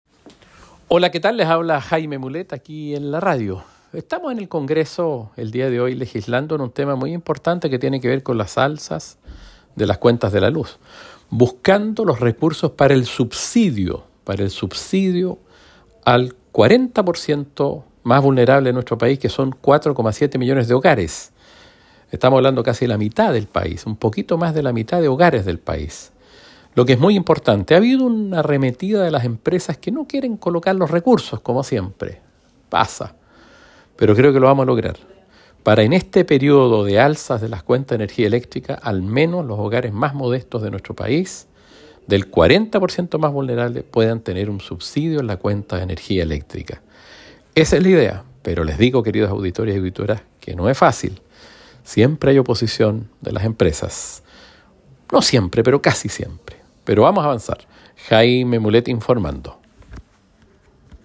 Audio podcast del diputado Jaime Mulet informando